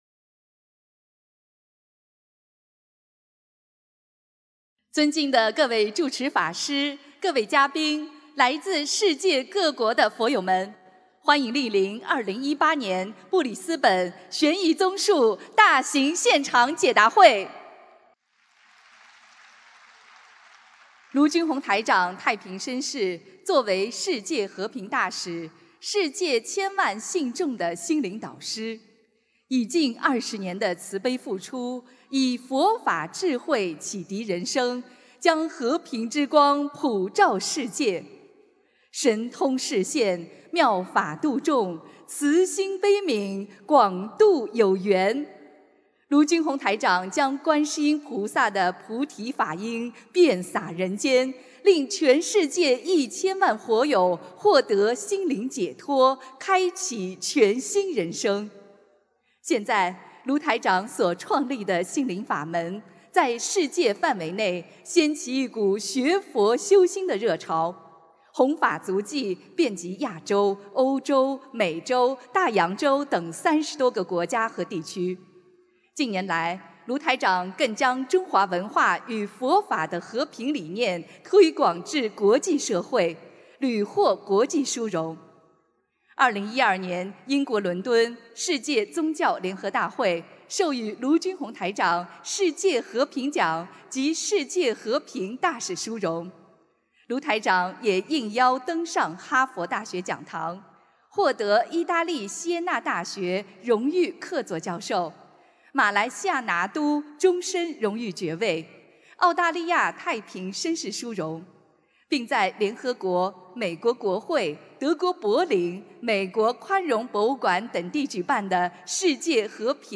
2018年6月17日澳大利亚布里斯本大法会（视音文图） - 2018年 - 心如菩提 - Powered by Discuz!